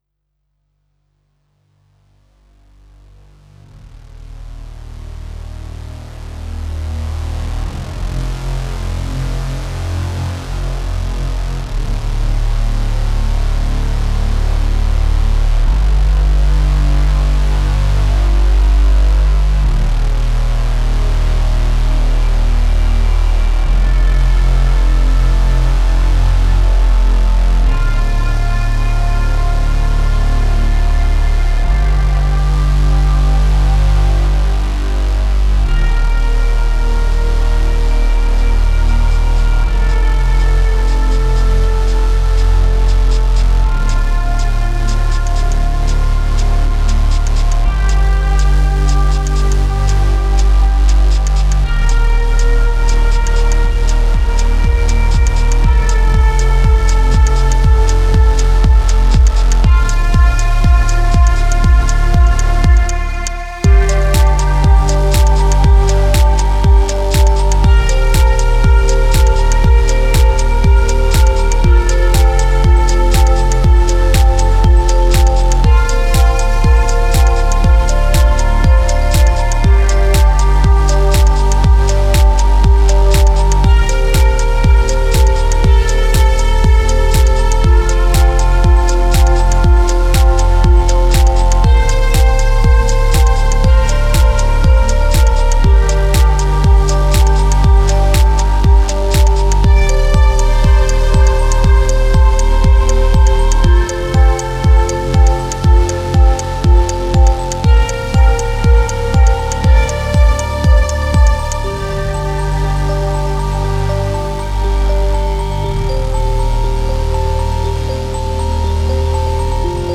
chill / downtempo / ambient